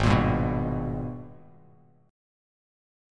G#mM9 chord